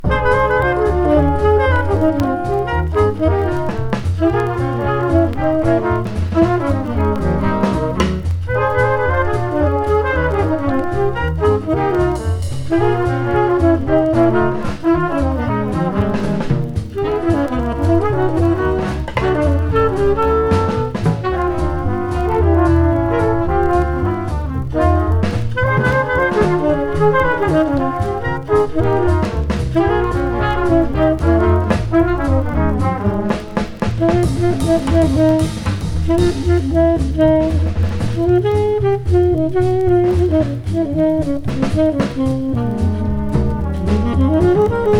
Jazz, Cool Jazz　USA　12inchレコード　33rpm　Mono